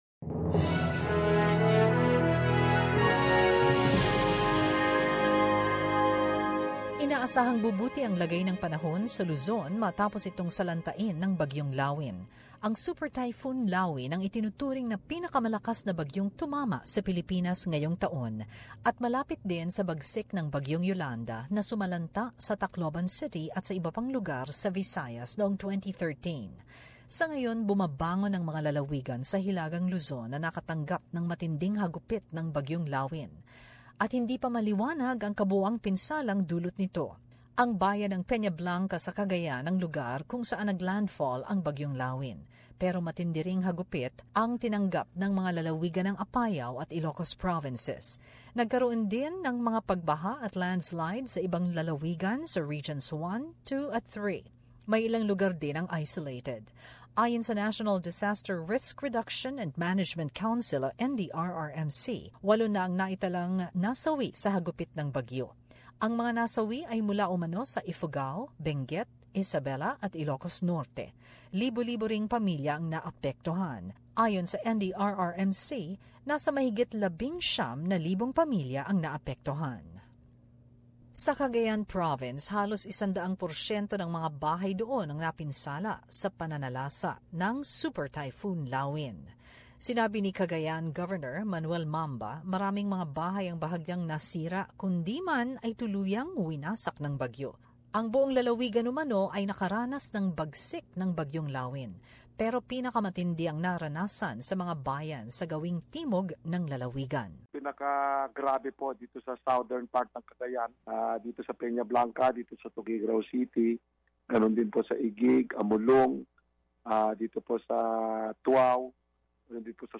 Report on Typhoon Lawin (Haima) and affected areas